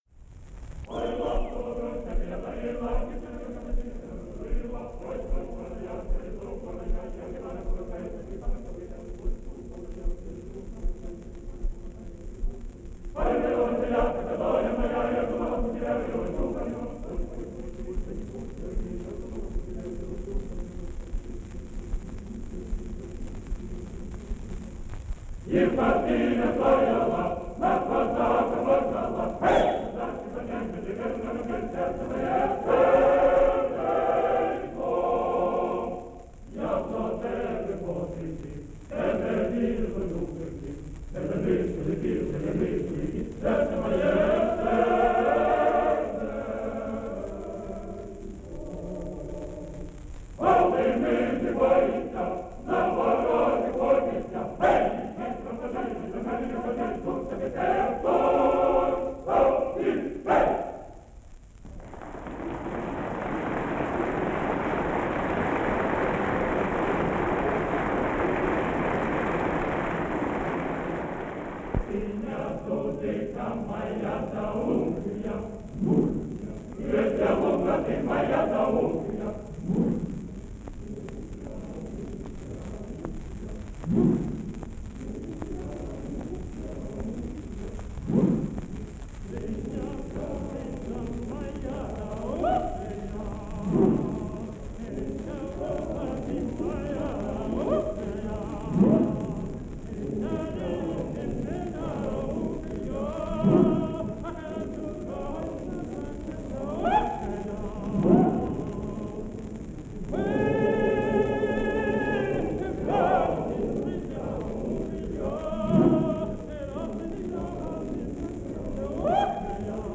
The voices are young and fresh.
Two Cossack Songs: